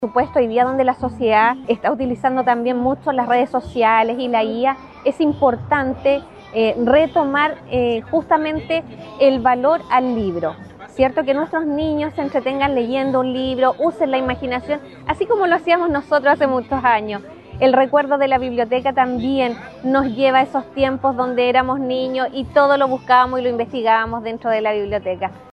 Marina-Matus-concejal-y-pdrta-Comision-de-educaciomn-valora-el-libro-.mp3